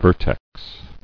[ver·tex]